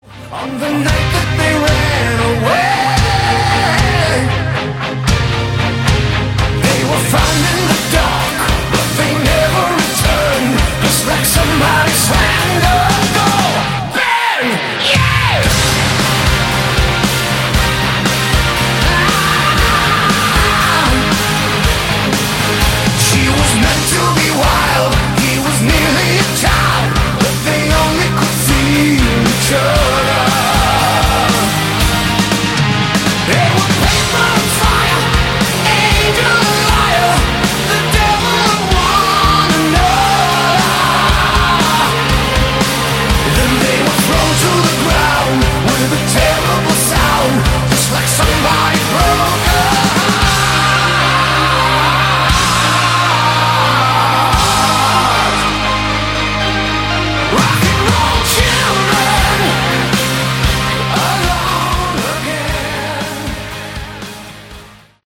Category: Melodic Metal
vocals
drums
bass
guitars